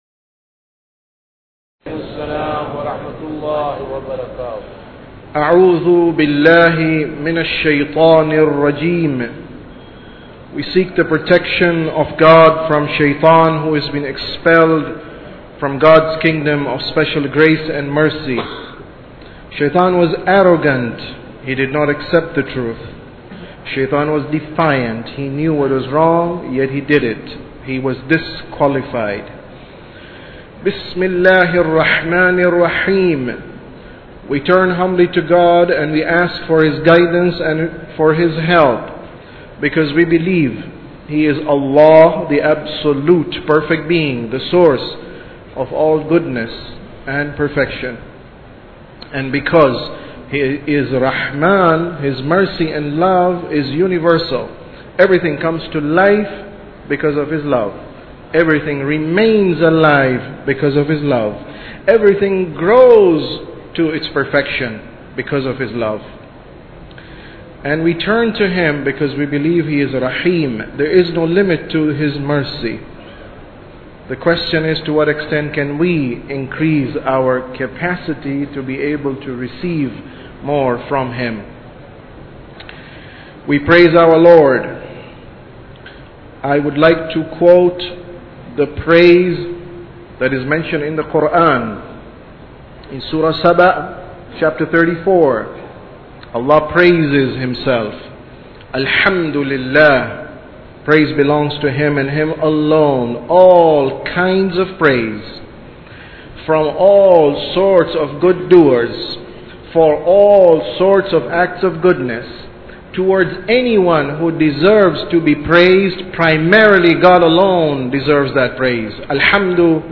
Sermon About Tawheed 4